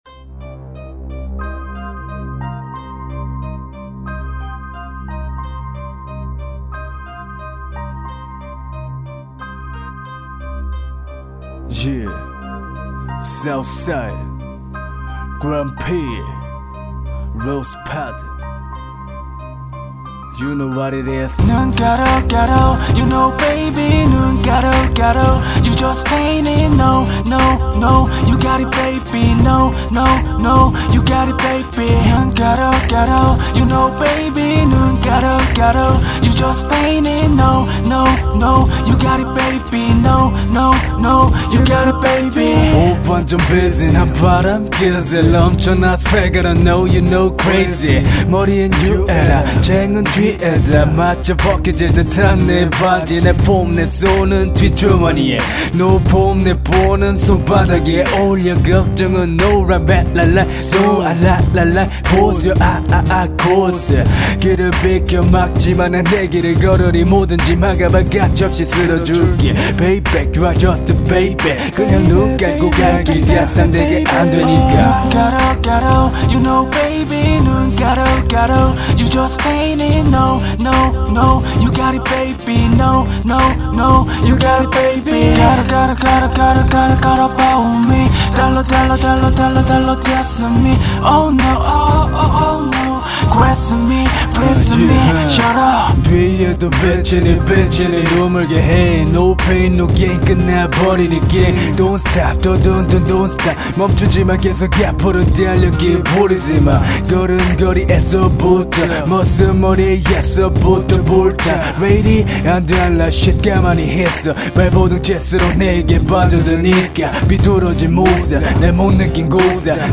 목소리 방용국 같으시네 ㅋ